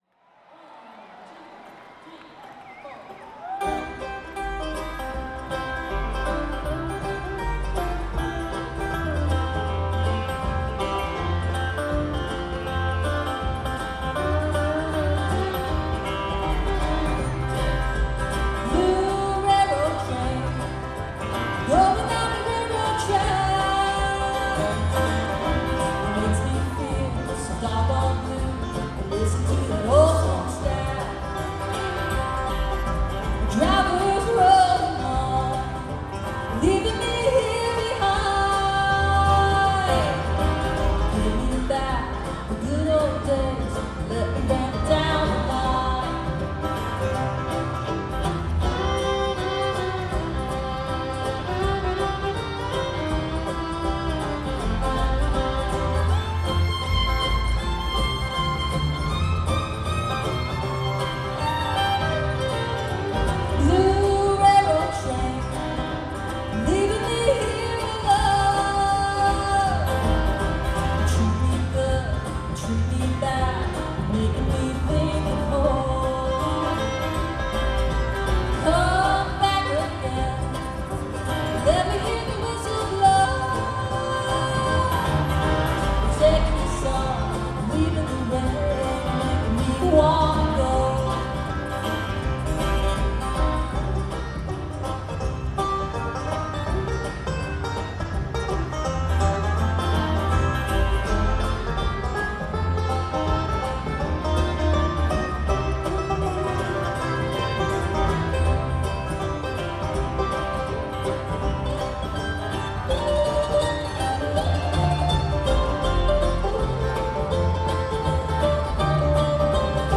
Here are two of my favorite artists playing together.